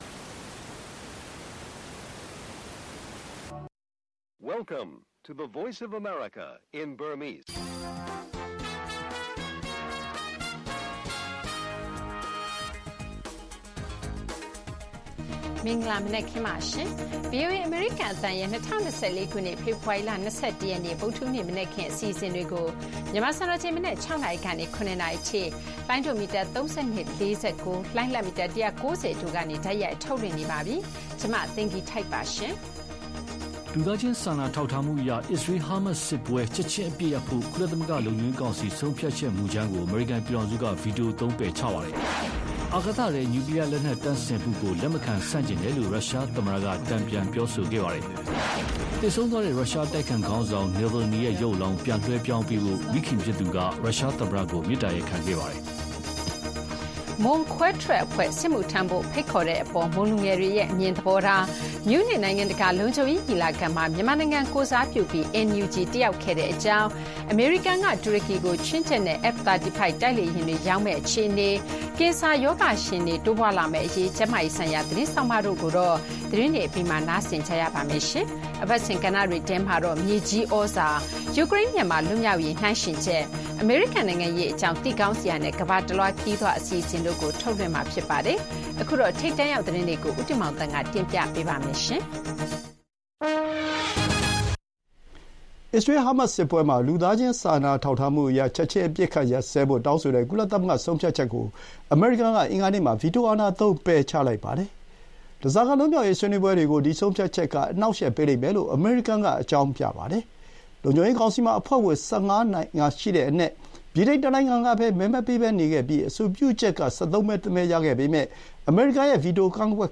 ဗွီအိုအေမြန်မာနံနက်ခင်း(ဖေဖော်ဝါရီ ၂၁၊၂၀၂၄) ဂါဇာအပစ်ရပ်ရေး ကုလလုံခြုံရေးကောင်စီဆုံးဖြတ်ချက် ကန် ဗီတိုသုံးပယ်ချ၊ မြူးနစ်နိုင်ငံတကာလုံခြုံရေးညီလာခံ မြန်မာနိုင်ငံကိုယ်စားပြုပြီး NUG တတ်ရောက်ခဲ့ စတဲ့သတင်းတွေနဲ့ အပတ်စဉ်အစီအစဉ်တွေ ထုတ်လွှင့်တင်ဆက်ပေးပါမယ်။